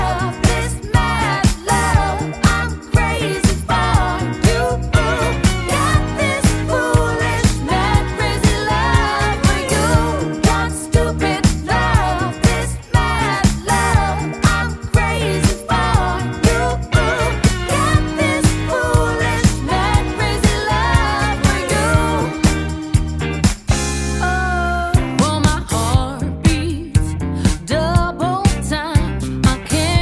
chant...